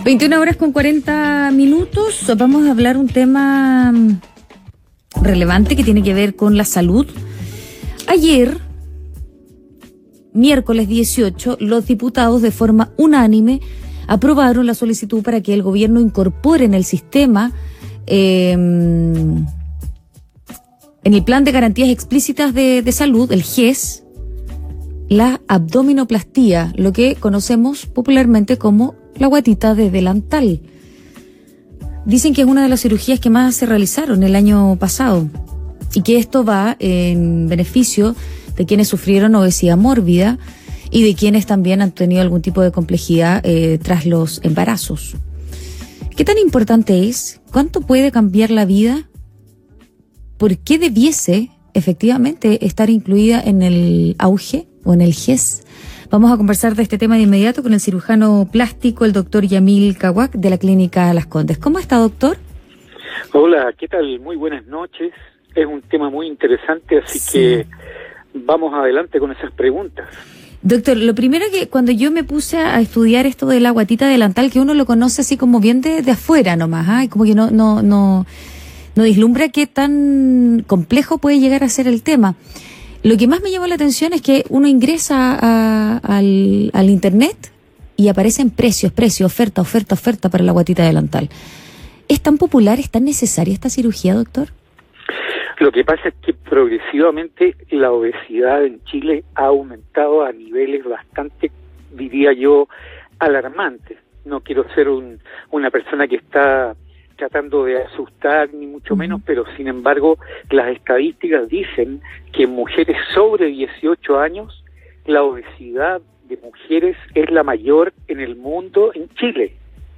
Escucha lo que fue esta entrevista realizada en el programa «Hoy en la Radio» acá: